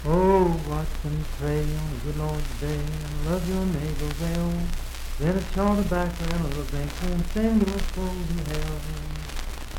Unaccompanied vocal music
Voice (sung)
Marlinton (W. Va.), Pocahontas County (W. Va.)